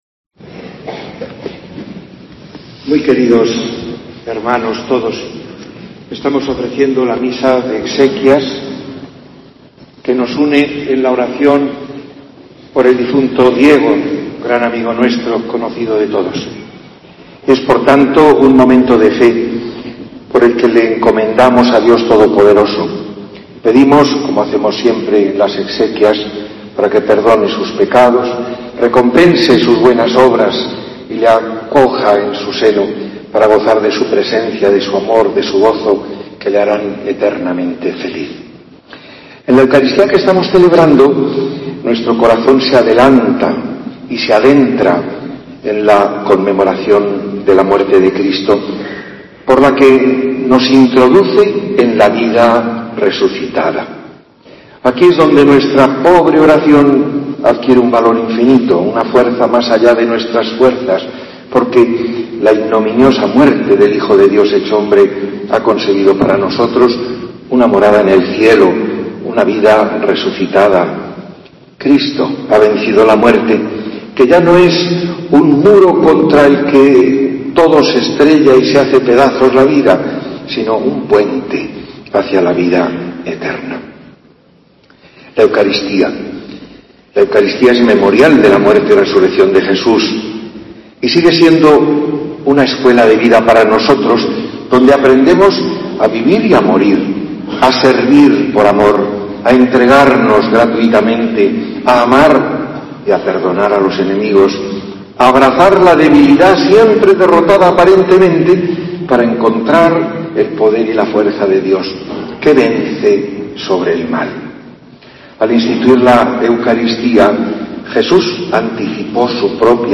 Homilía de Monseñor Rafael Zornoza, Obispo de Cádiz y Ceuta en el funeral tras el asesinato en Algeciras